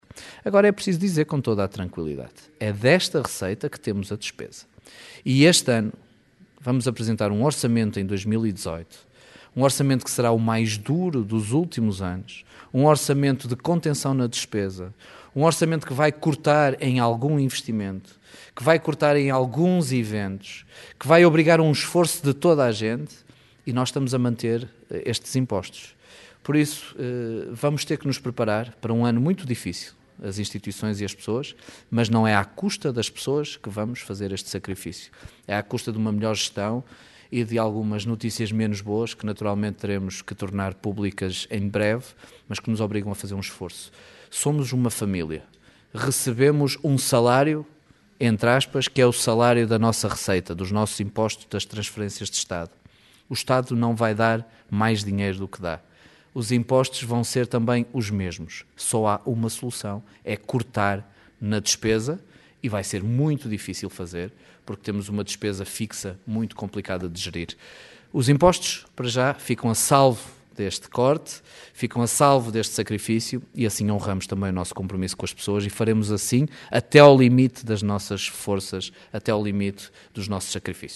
Mas para manter os impostos nos valores mais baixos o presidente da Câmara avisa que terá que haver cortes noutras despesas e lembra uma vez mais que o orçamento para 2018, que será apresentado em Janeiro, será um orçamento duro em termos de contenção de despesa.